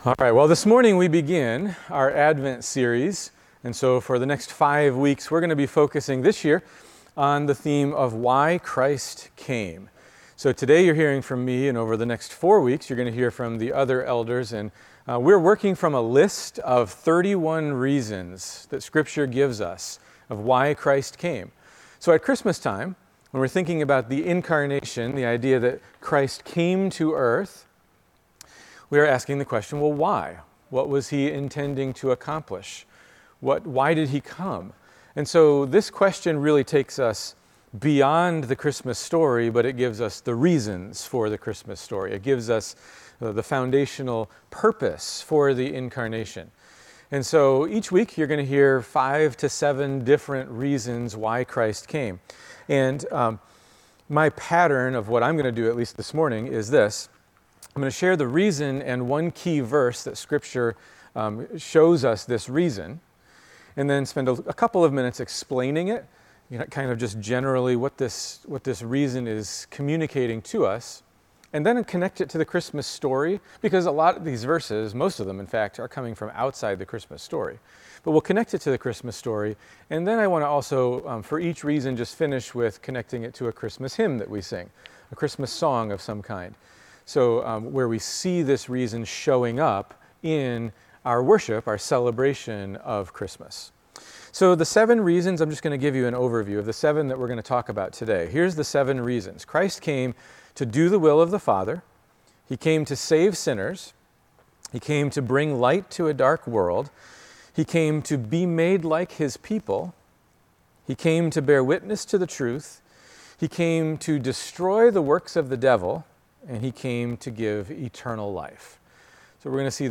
Sermons from Ikon Church: Wadsworth, OH